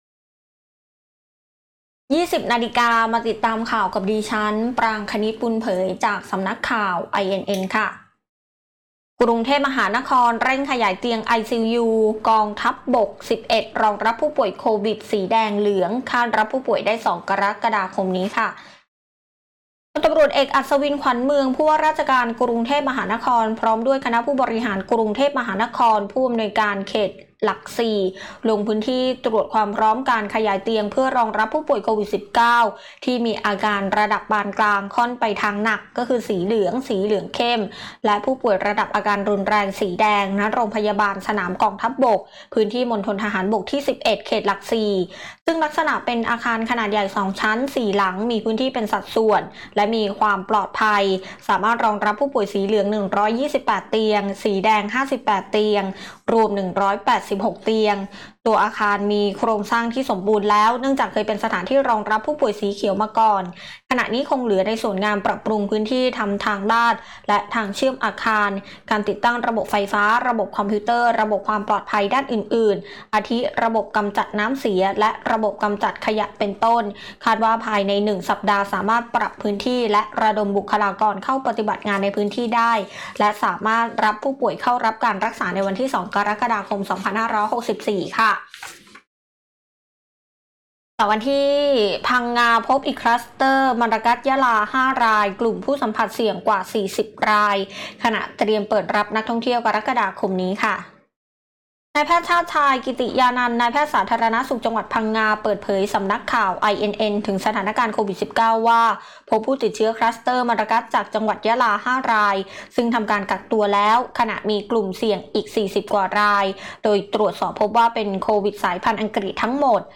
คลิปข่าวต้นชั่วโมง
ข่าวต้นชั่วโมง 20.00 น.